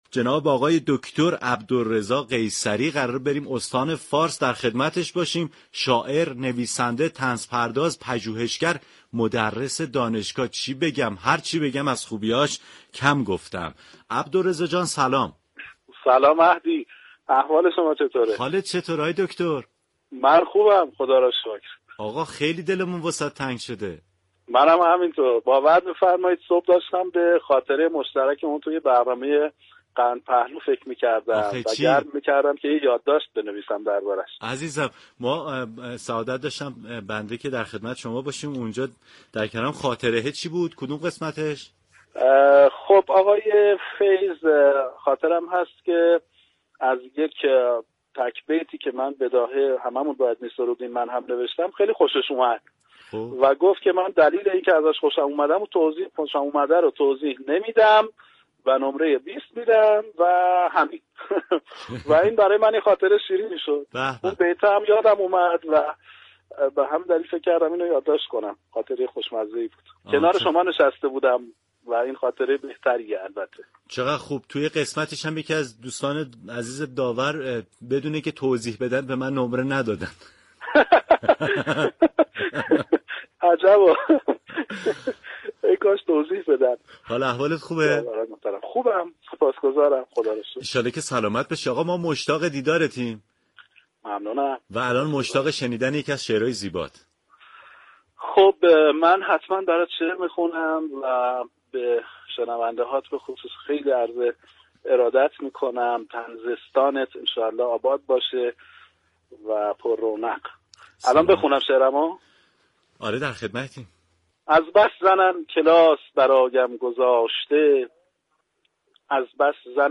اثری طنز از او با خوانش خود شاعر بشنوید.